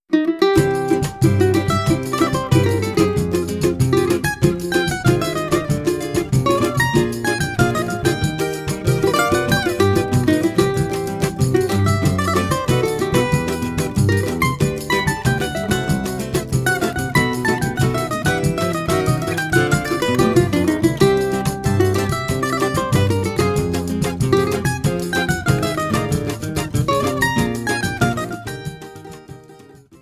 cavaquinho
Choro ensemble